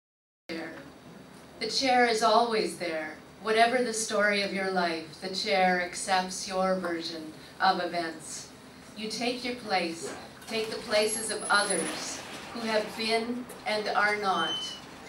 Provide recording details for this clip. A crowd of about 30 gathered in the Tipsy Muse Café on Oct. 20 for Odd Sunday’s poetry and literature reading series. Each scheduled poet read for about 20 minutes, then an open mic session invited anyone to the stage to recite poetry.